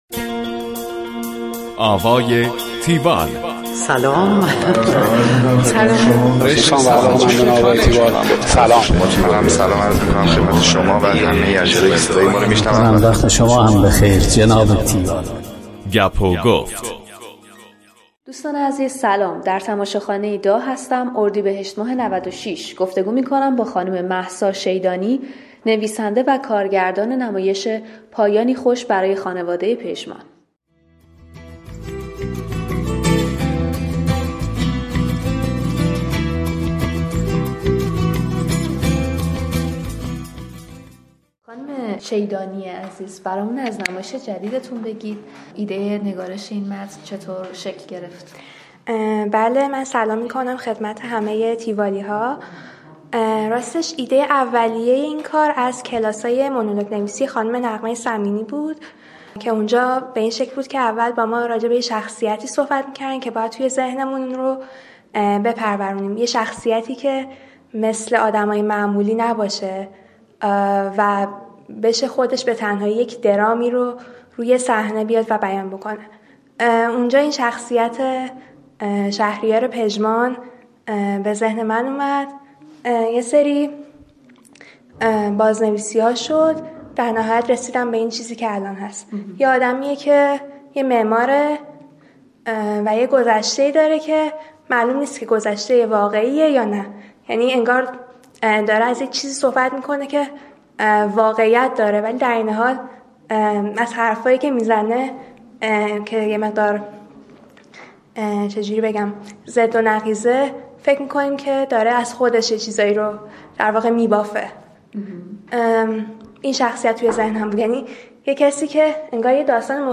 گفتگو کننده: